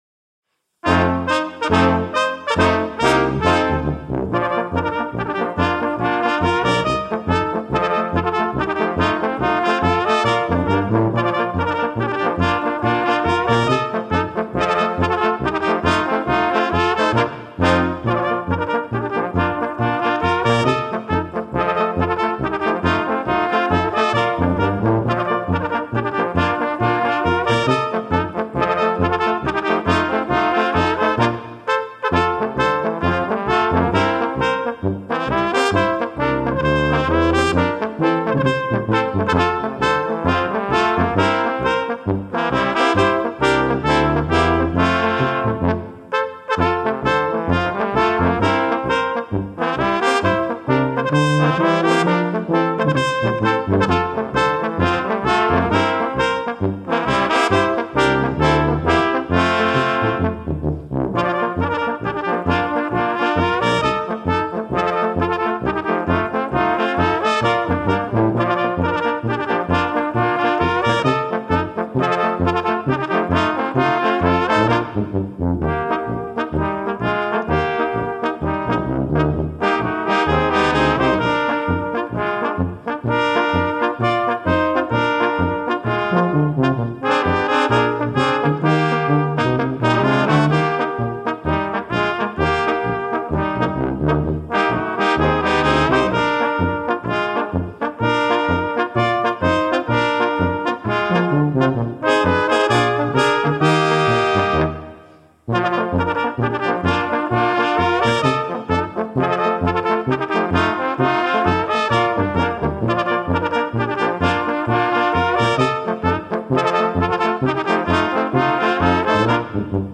Gattung: Volkstümliche Stücke für vier oder fünf Bläser
Besetzung: VOLKSMUSIK Weisenbläser